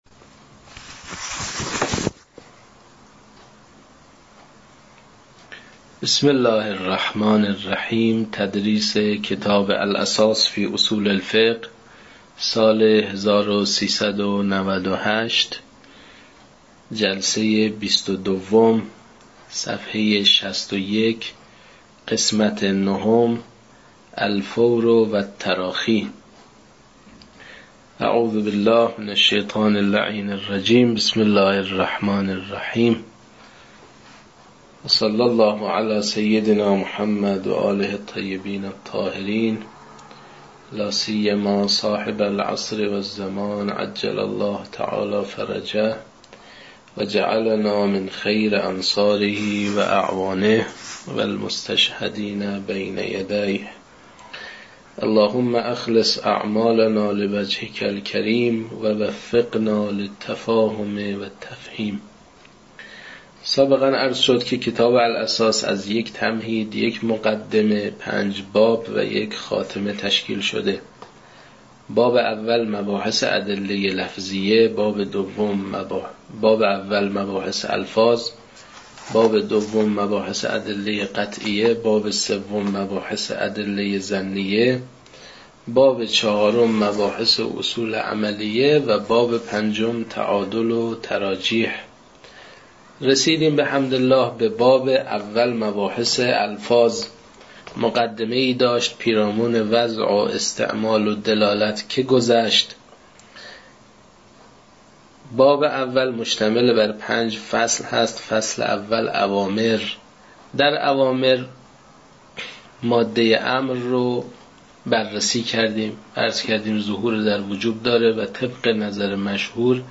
در این بخش، کتاب «الاساس» که اولین کتاب در مرحلۀ آشنایی با علم اصول فقه است، به صورت ترتیب مباحث کتاب، تدریس می‌شود.
در تدریس این کتاب- با توجه به سطح آشنایی کتاب- سعی شده است، مطالب به صورت روان و در حد آشنایی ارائه شود.